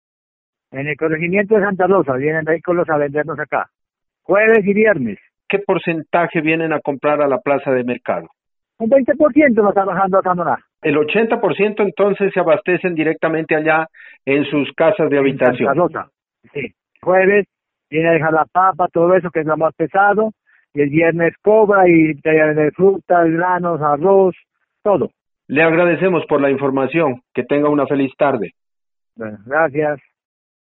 A los líderes comunitarios les hicimos la misma pregunta: ¿En la actualidad en donde se abastecen de alimentos las familias de su corregimiento?